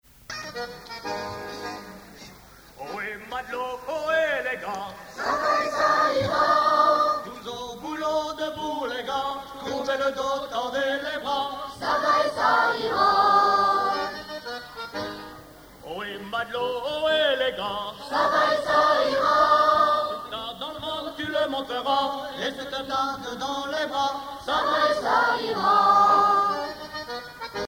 Mor-Gan Chorale
Chansons de la soirée douarneniste 88
Pièce musicale inédite